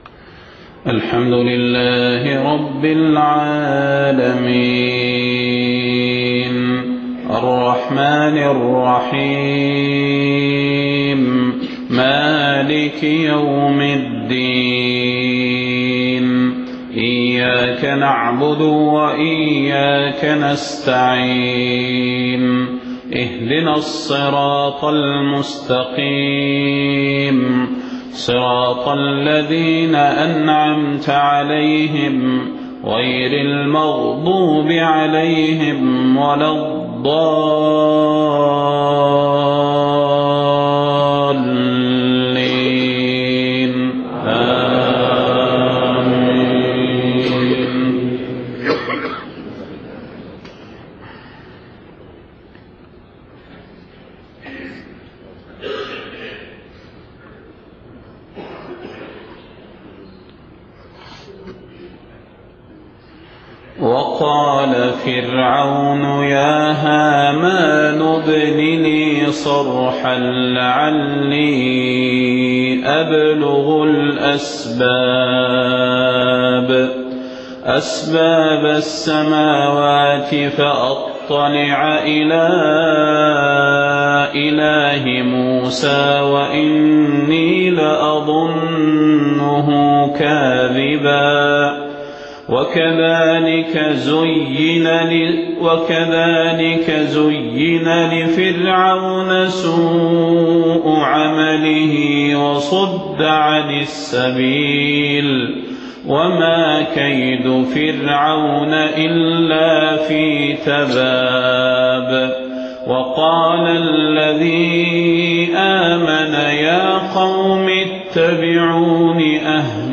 صلاة الفجر 29 محرم 1430هـ من سورة غافر36-52 > 1430 🕌 > الفروض - تلاوات الحرمين